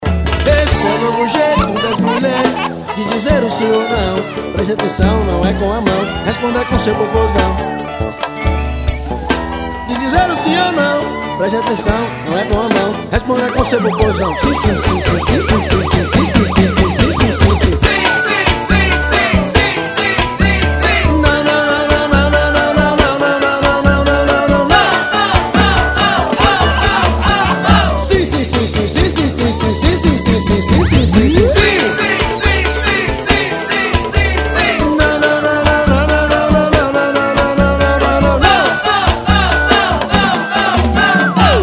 Alguns sucessos de Pagodes Baiano.